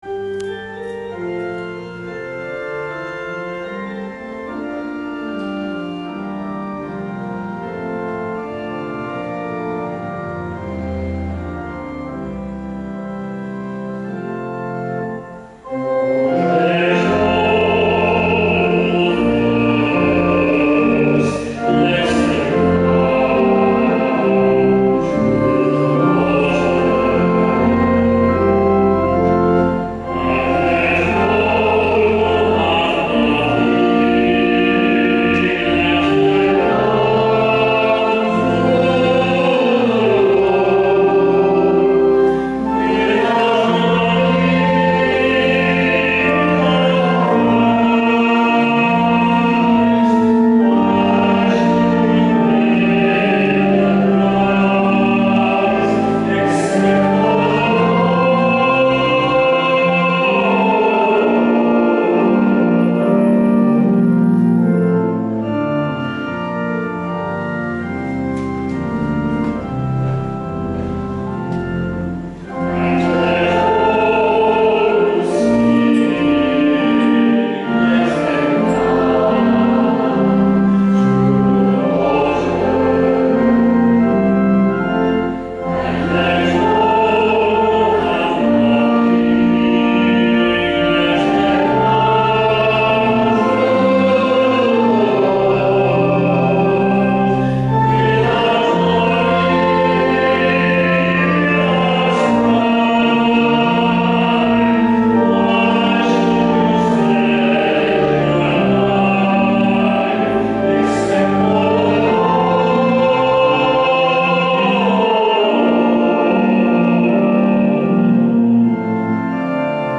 Easter Vigil 2013
Cantor